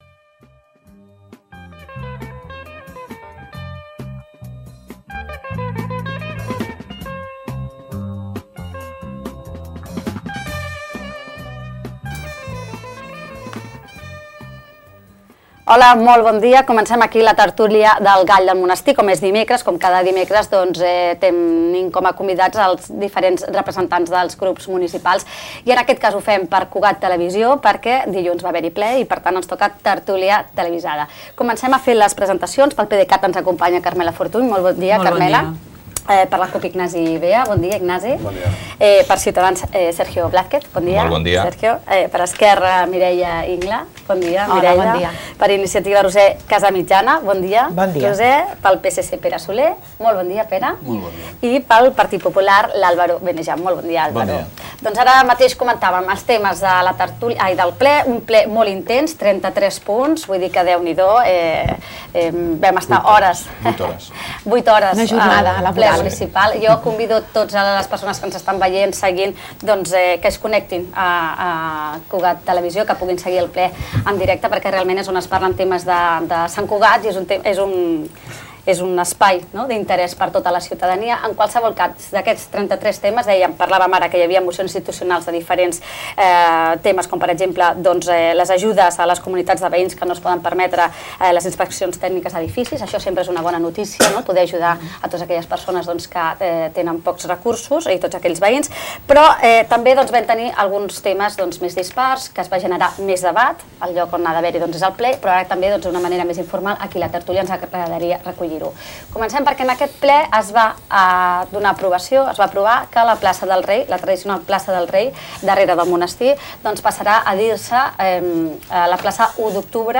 La tert�lia 'El Gall del Monestir' ha tingut avui de convidats els grups municipals amb pres�ncia al ple municipal.